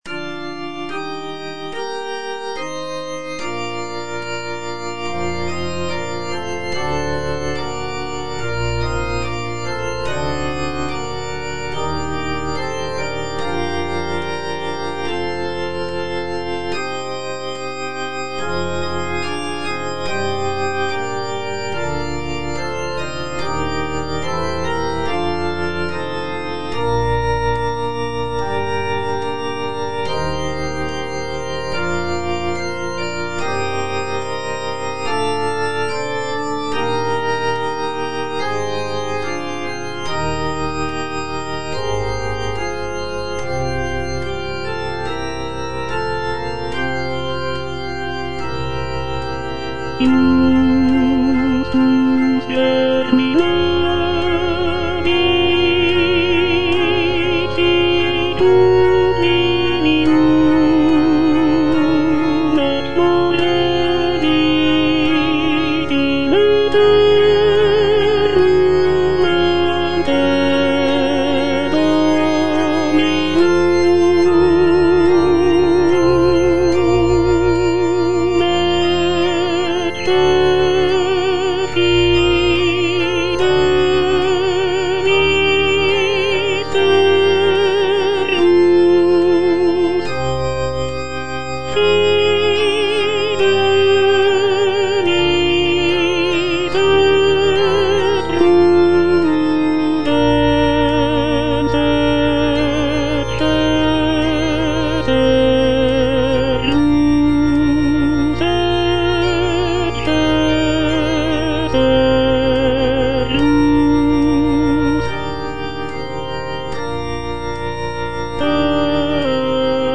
G. FAURÉ - ECCE FIDELIS SERVUS Tenor (Voice with metronome) Ads stop:
"Ecce fidelis servus" is a sacred choral work composed by Gabriel Fauré in 1896. The piece is written for four-part mixed choir and organ, and is based on a biblical text from the Book of Matthew.